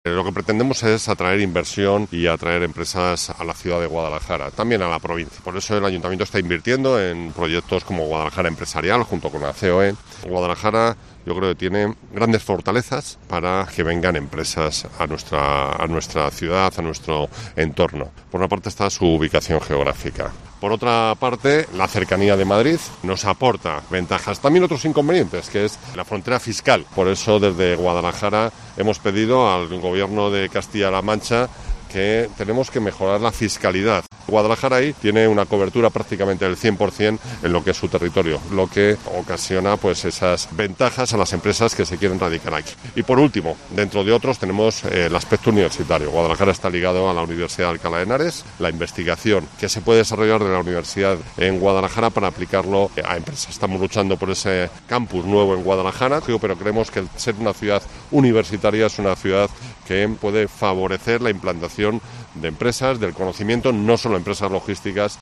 El Alcalde, Antonio Román, señala las fortalezas de la ciudad